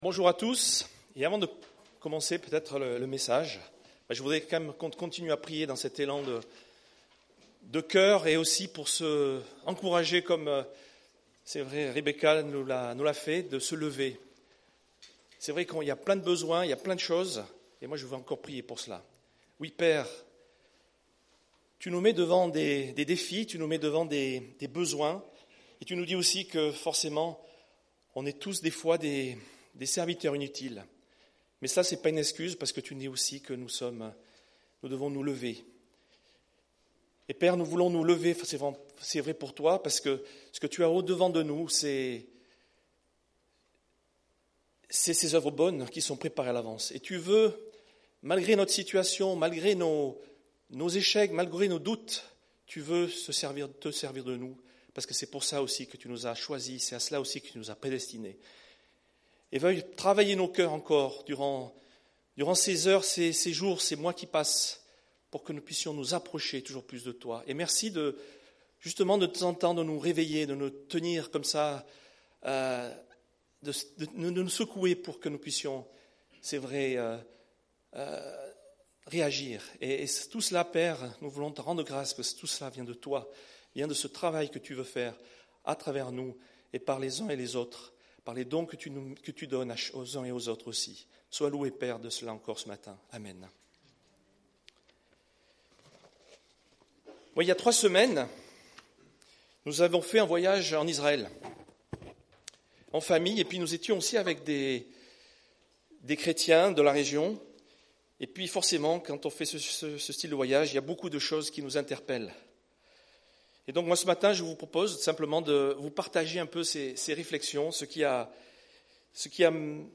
Culte du 17 novembre